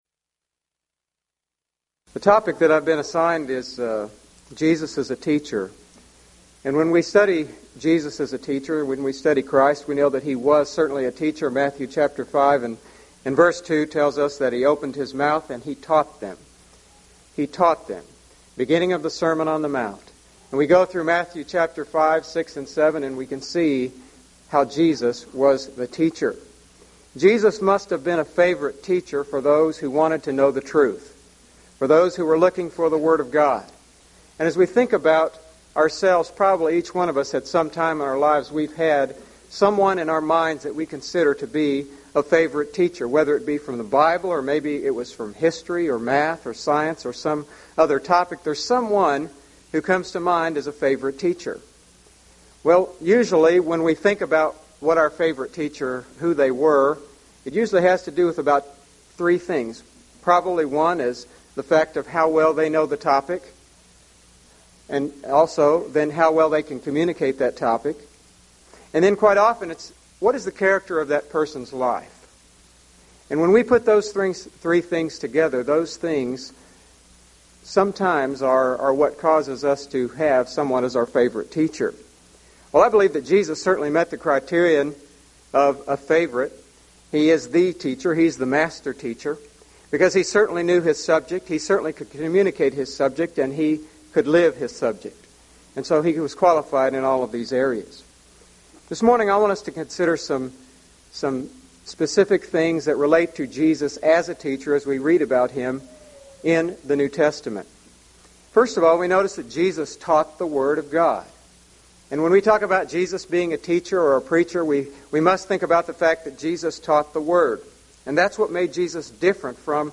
Event: 1998 Mid-West Lectures
lecture